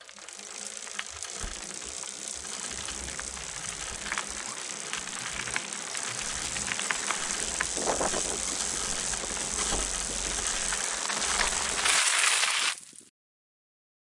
Foundation Braking » Brake Concrete High Speed OS
描述：Mountain Bike Braking on Concrete
标签： Braking Concrete MountainBike
声道立体声